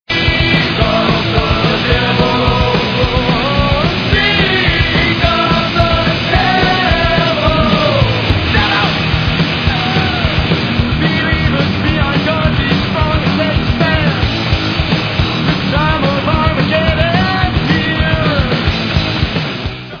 Rock/Hardcore